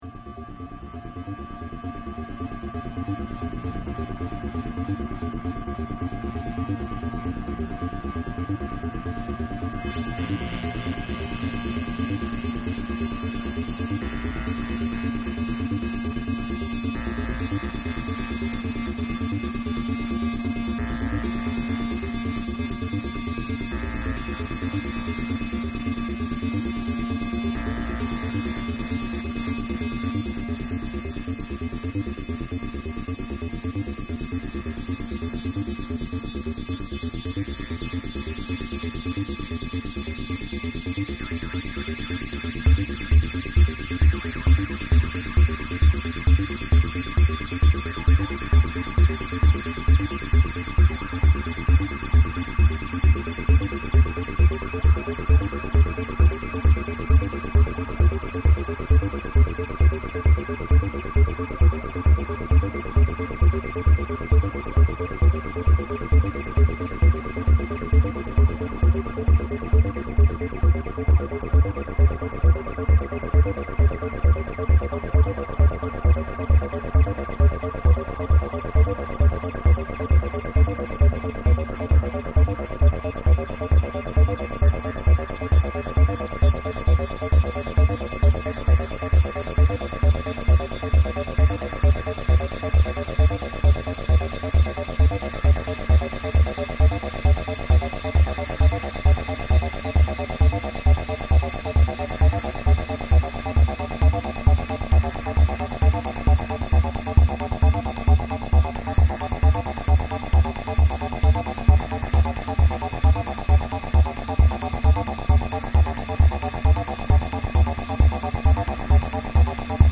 it is pure random chance that this acid jam is 7:11mins long